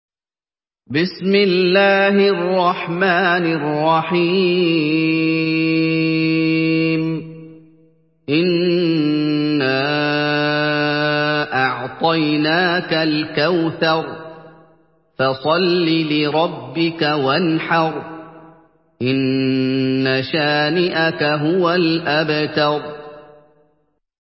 تحميل سورة الكوثر بصوت محمد أيوب
مرتل حفص عن عاصم